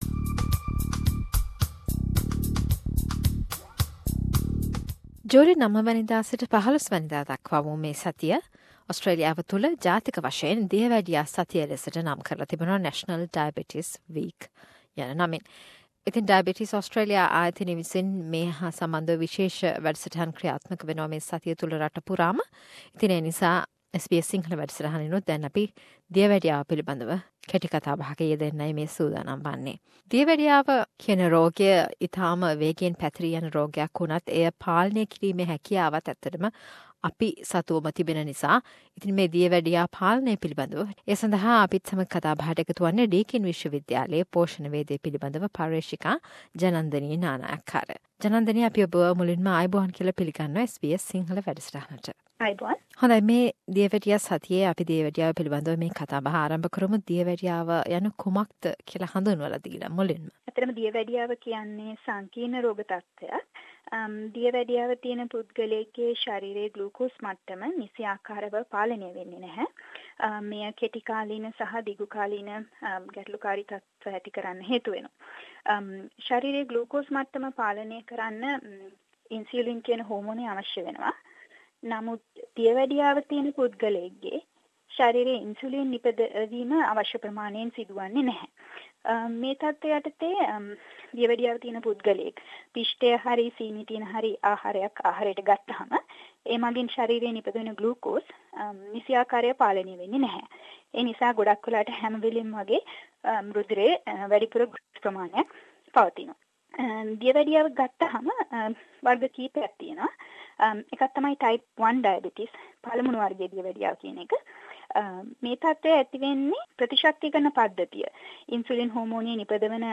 A discussion to Mark National Diabetes week